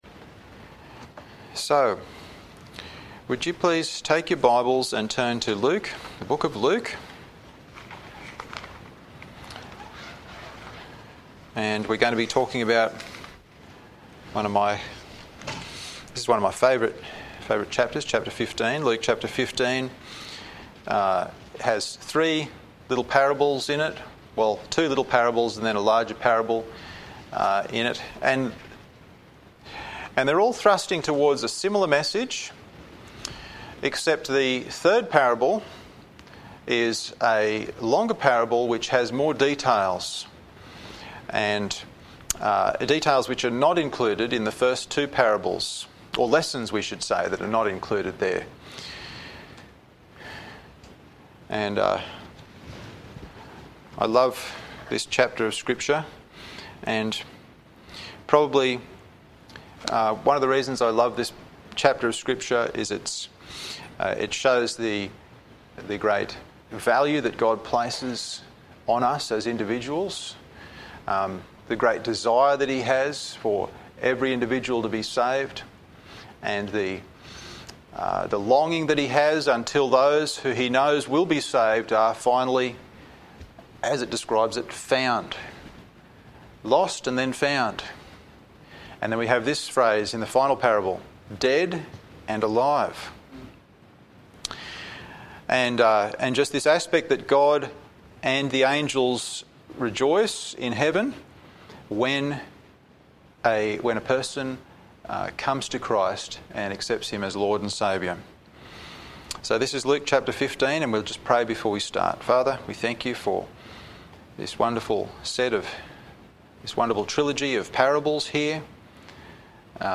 Sermons | TBBC | Tamworth Bible Baptist Church